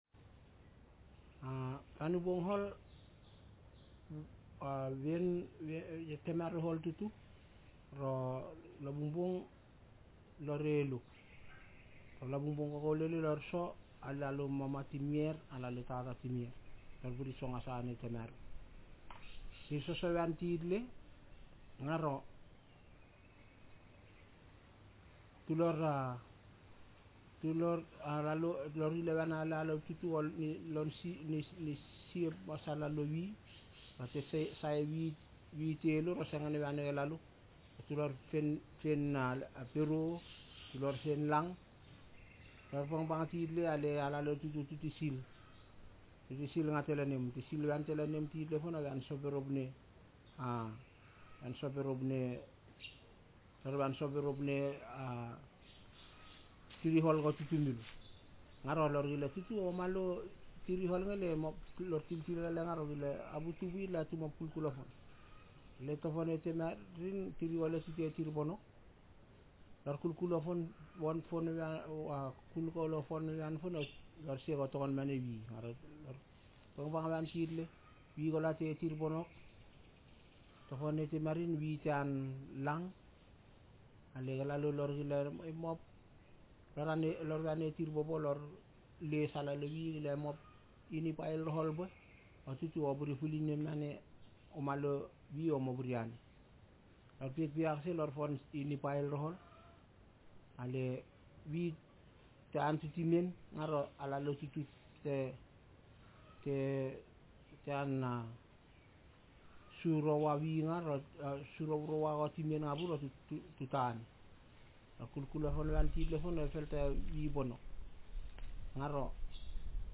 Speaker sexm
Text genretraditional narrative